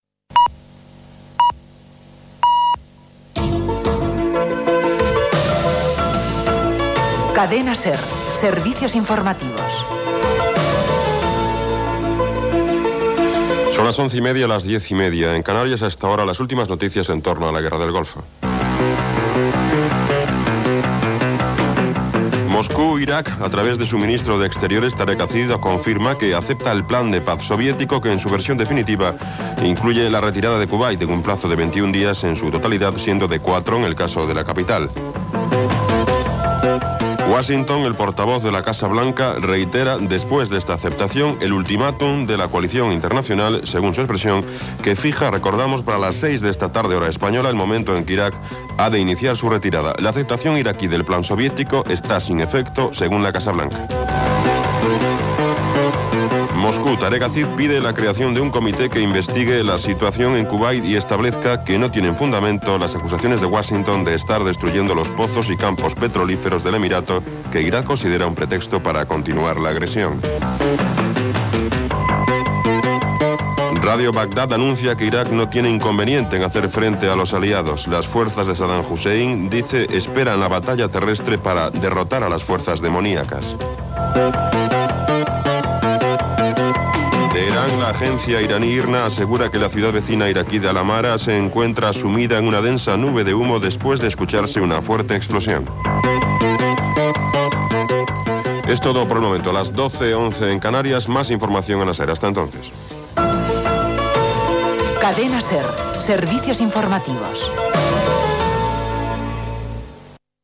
Senyals horaris. Careta d'entrada del programa. Informatiu sobre la Guerra del Golf Pèrsic entre Iran i Iraq. Careta de sortida.
Informatiu